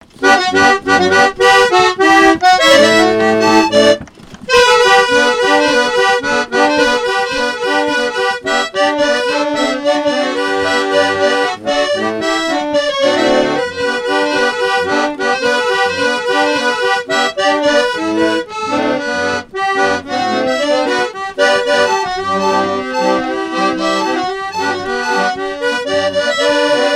Marche jouée sur un orgue Mignon
musique mécanique
Pièce musicale inédite